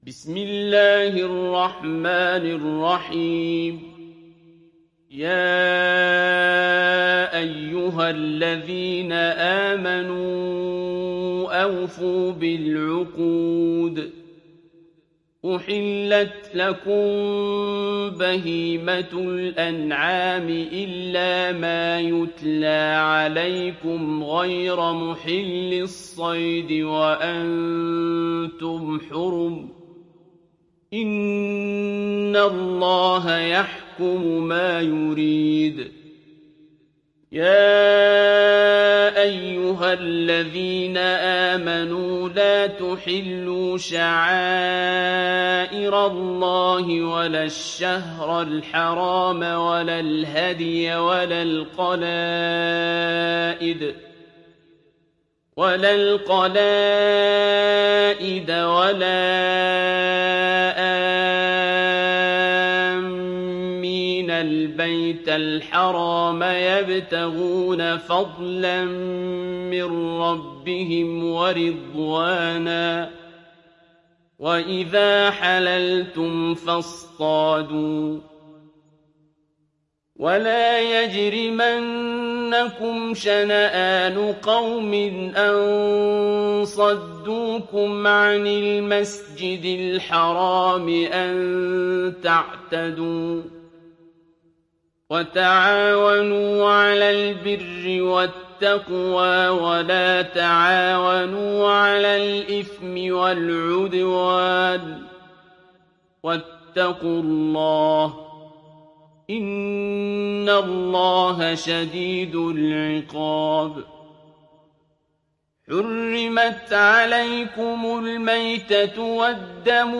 Surah Al Maidah mp3 Download Abdul Basit Abd Alsamad (Riwayat Hafs)